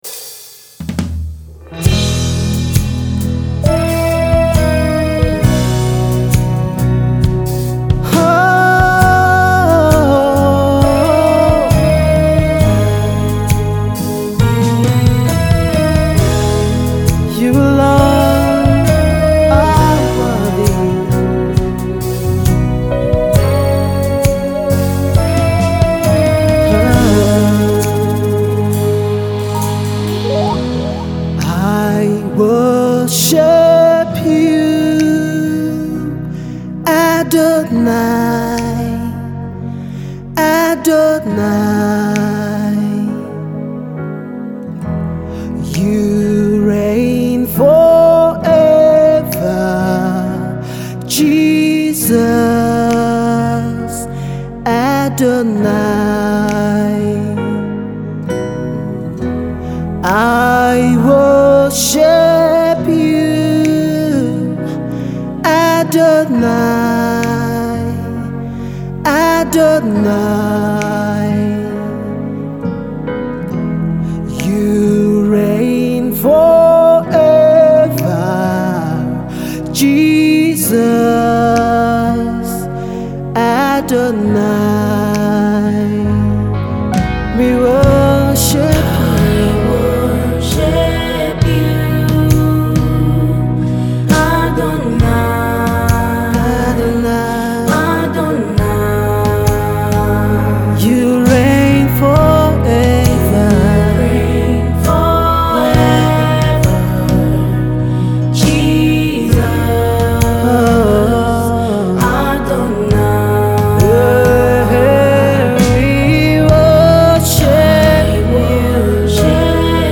deep song of worship